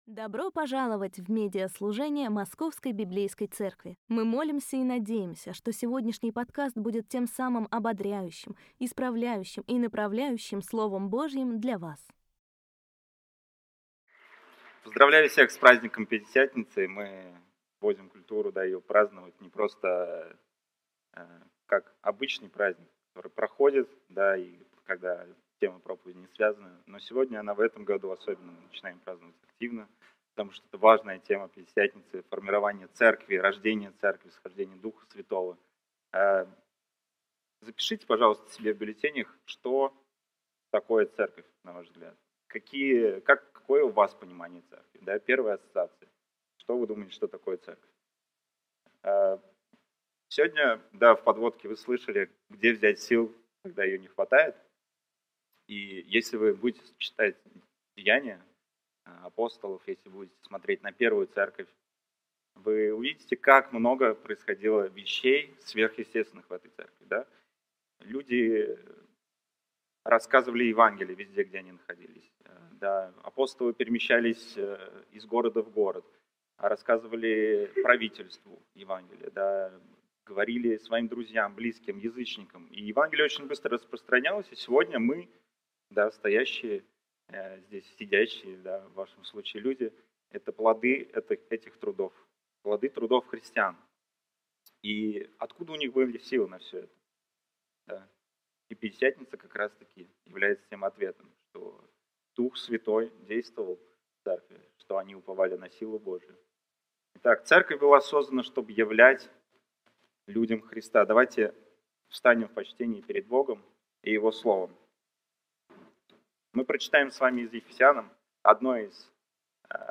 Pentecost+2025+SERMON.mp3